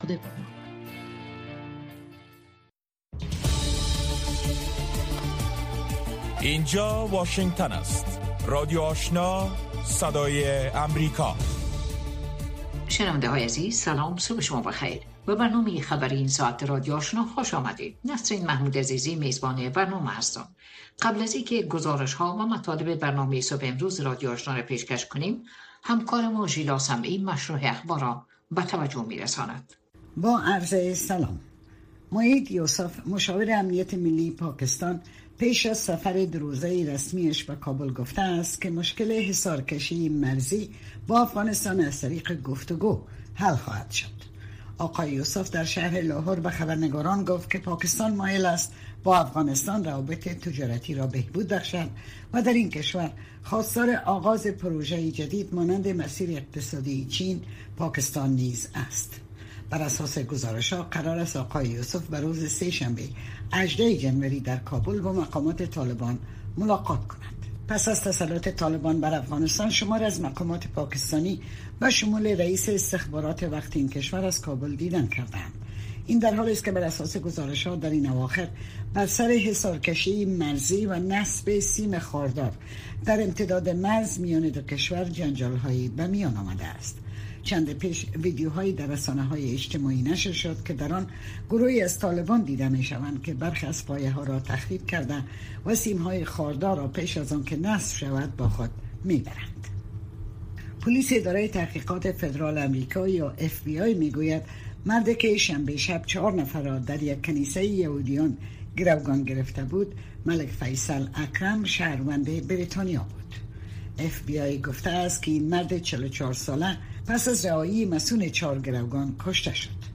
نخستین برنامه خبری صبح